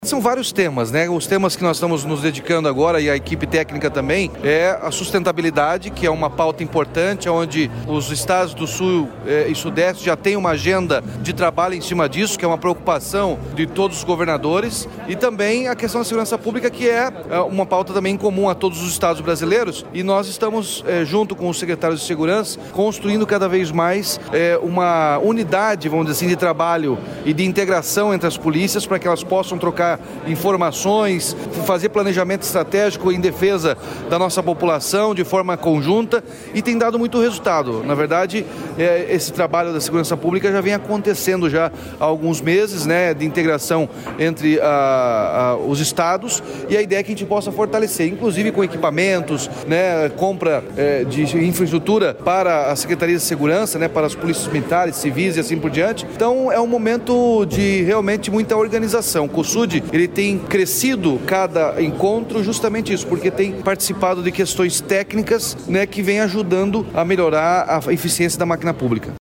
Sonora do governador Ratinho Junior sobre a proposta do Cosud de maior integração interestadual para fortalecimento da segurança pública